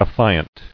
[af·fi·ant]